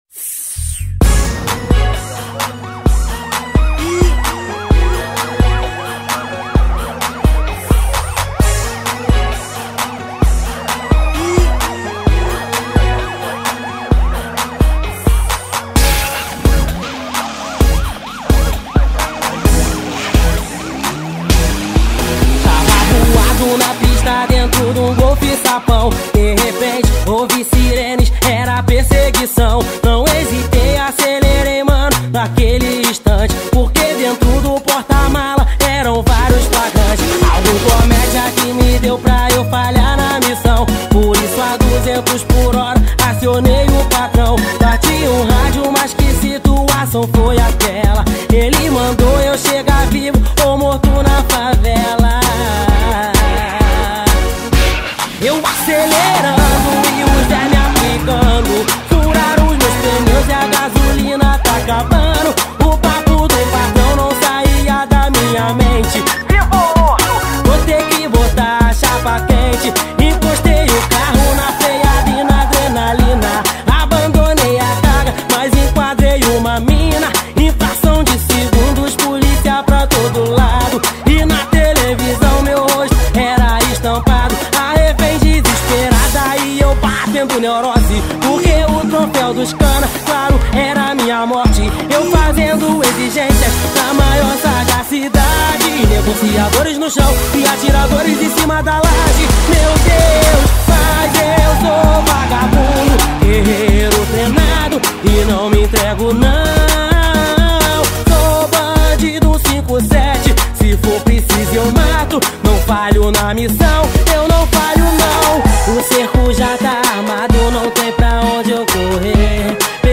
2025-04-07 08:24:54 Gênero: Rap Views